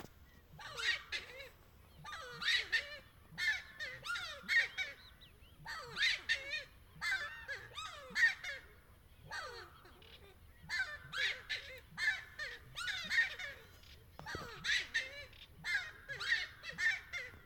Giant Wood Rail (Aramides ypecaha)
Province / Department: Entre Ríos
Location or protected area: Villa Paranacito
Condition: Wild
Certainty: Observed, Recorded vocal